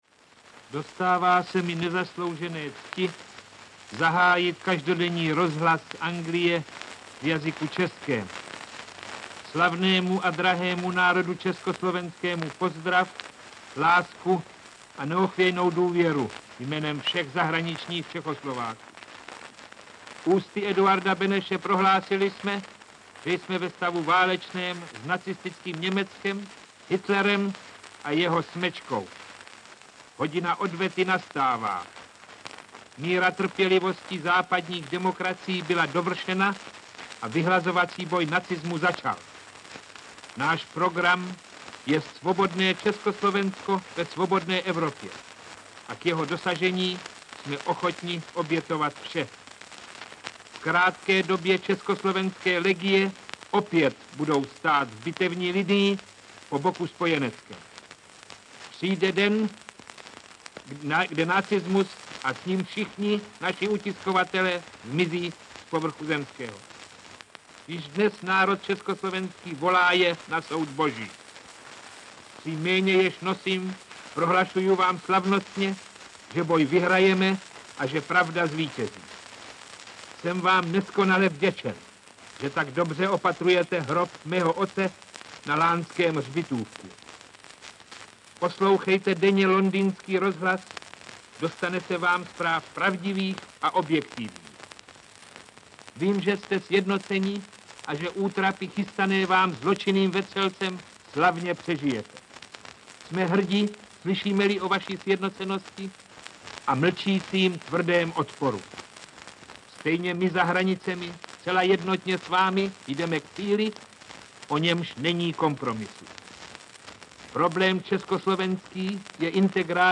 Ukázka z knihy
Snímky z gramofonových desek nebo zvukových fólií jsou sice poznamenány typickým praskotem desítky let starého mechanického záznamu, ale přesto můžete ocenit a vychutnat práci tehdejších mistrů zvuku mnohem lépe než posluchači, pro něž byly relace z Londýna určeny za války. Ruchy a hlasy totiž uslyšíte bez bublajícího povyku válečných rušiček příjmu krátkých vln.